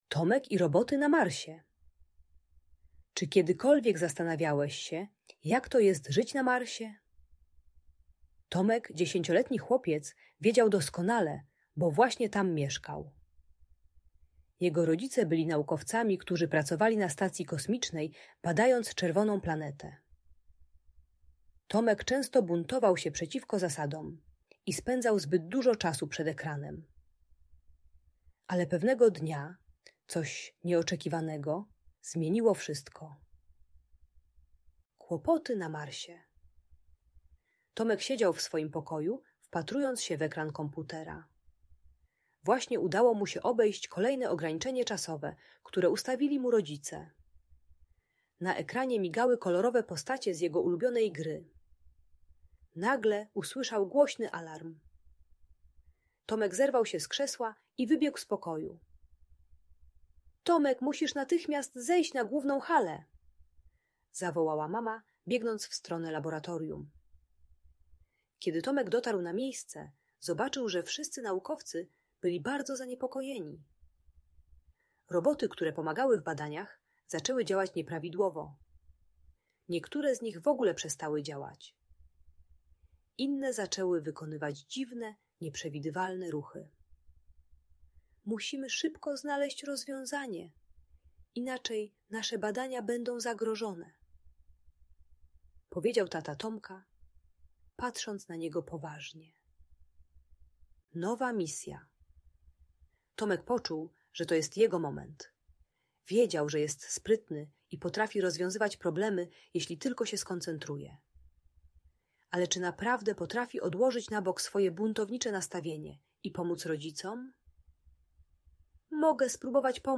Tomek i Roboty na Marsie - Bunt i wybuchy złości | Audiobajka